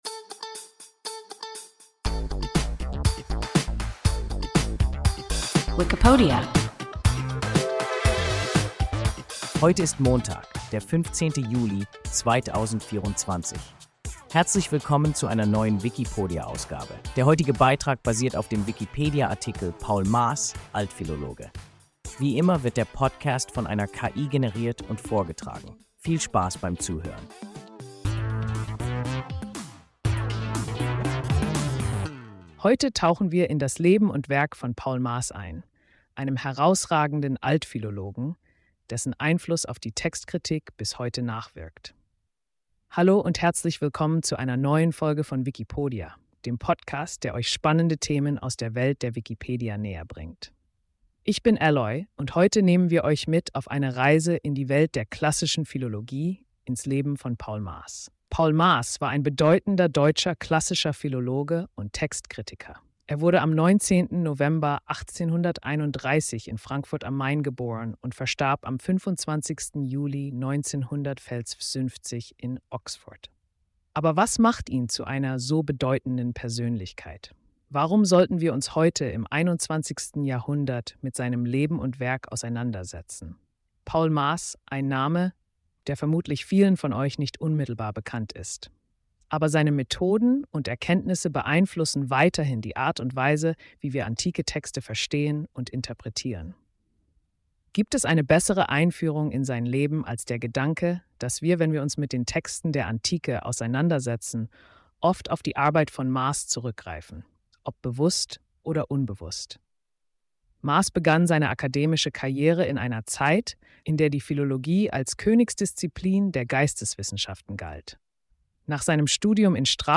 Paul Maas (Altphilologe) – WIKIPODIA – ein KI Podcast